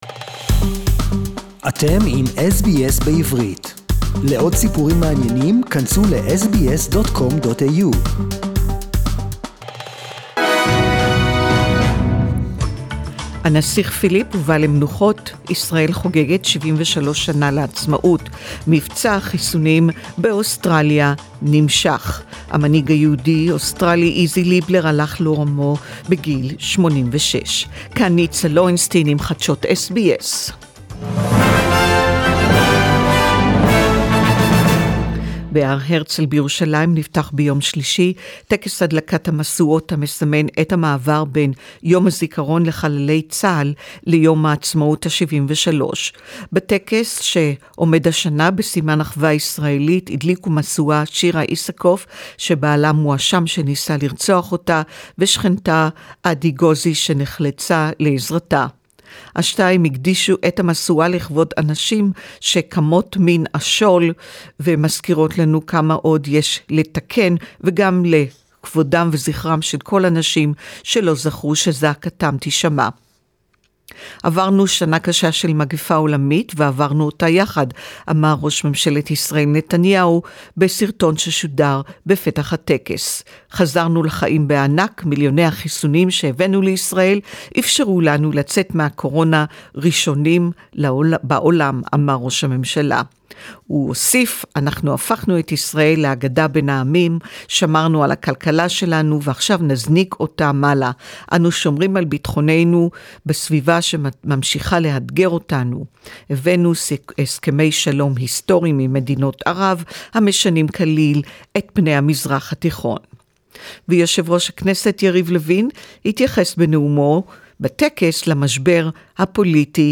SBS News in Hebrew 18.4.2021